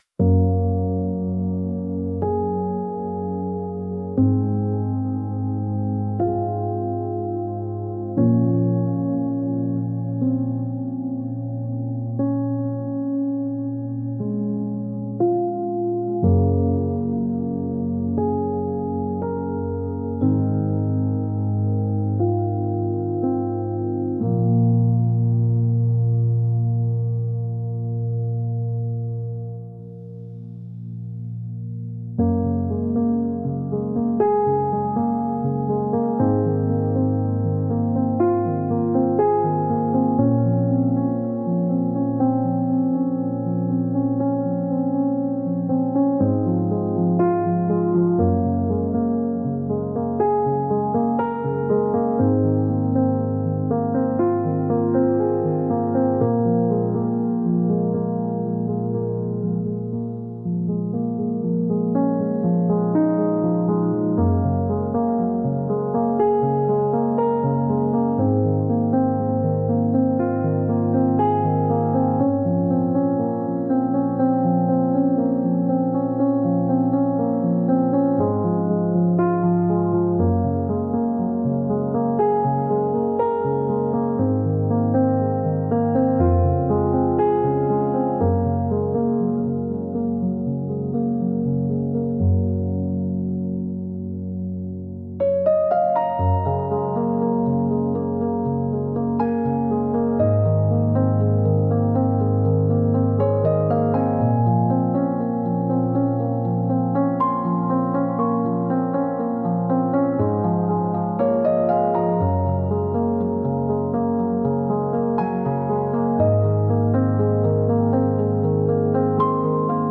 Instrumental: